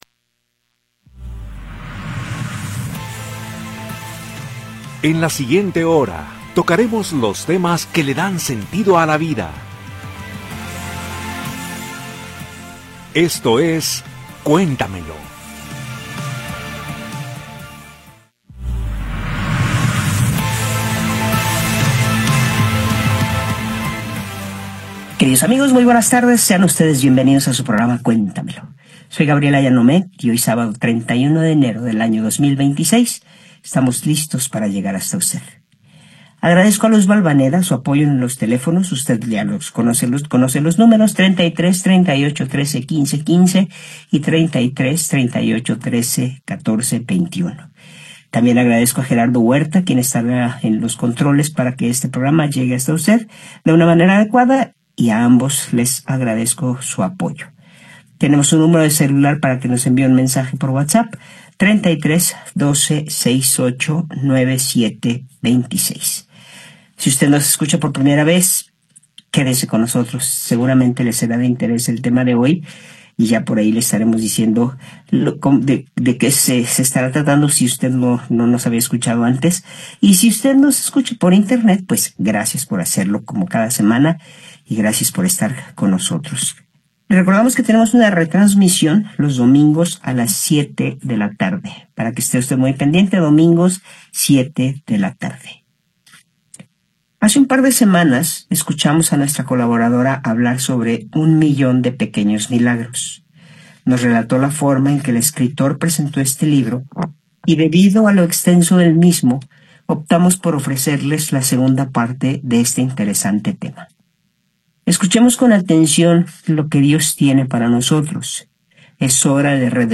en vivo con los temas que dan sentido a la vida.